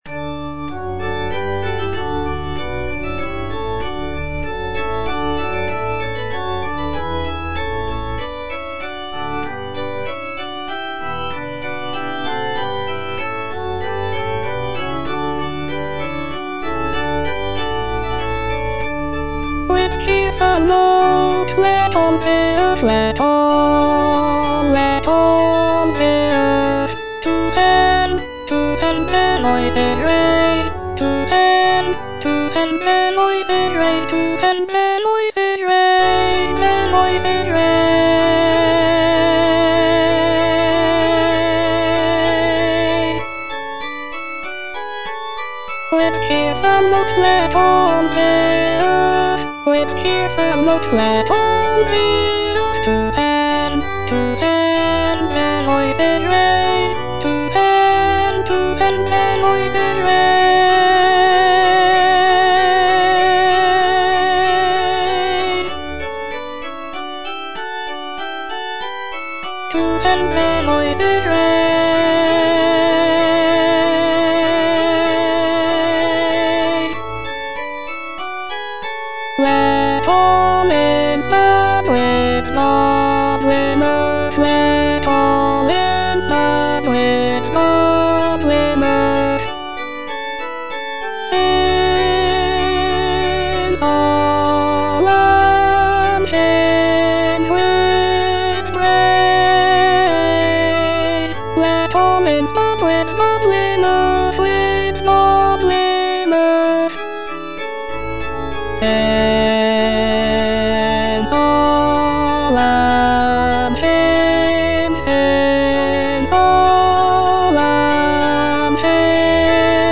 (A = 415 Hz)
Alto (Voice with metronome) Ads stop